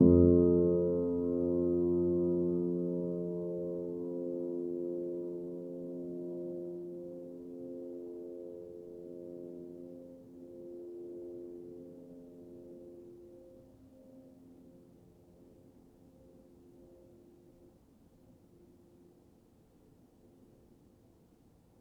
healing-soundscapes/Sound Banks/HSS_OP_Pack/Upright Piano/Player_dyn1_rr1_010.wav at ae2f2fe41e2fc4dd57af0702df0fa403f34382e7 - healing-soundscapes - Ligeti Zentrum Gitea